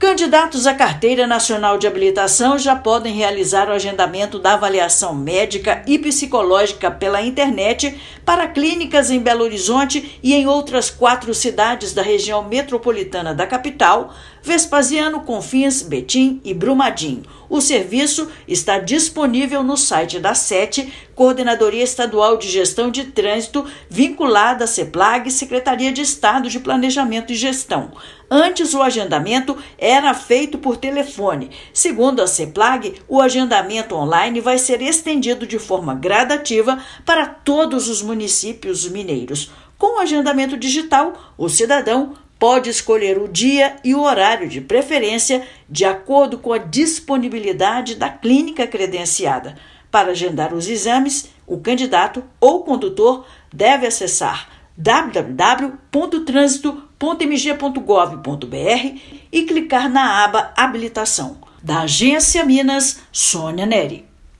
Em mais uma ampliação do acesso digital aos serviços de trânsito, o agendamento já está disponível em Belo Horizonte e em outras quatro cidades, e será estendido gradativamente aos demais municípios. Ouça matéria de rádio.